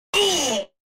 eughhhh red - Botón de Efecto Sonoro